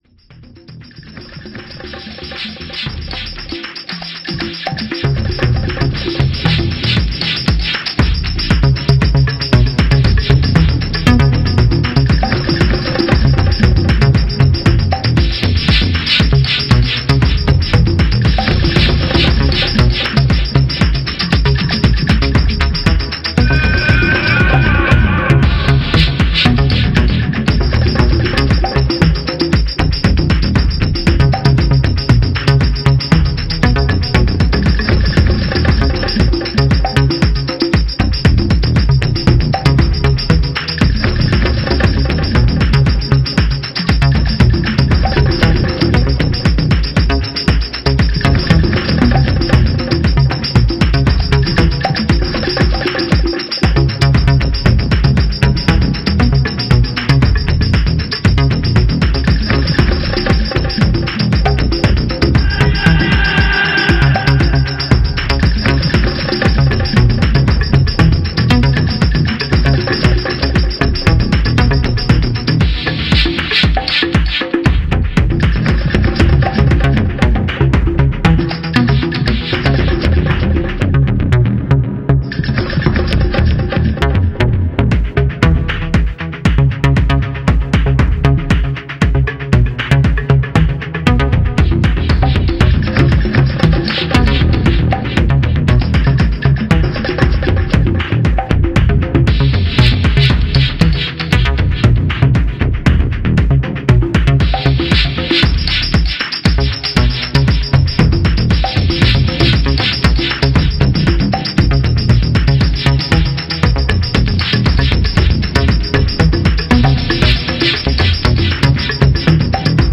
トライバルやアシッド要素、ダークでスペーシーなコードなどを用いながらロウでソリッドなハウスを展開していて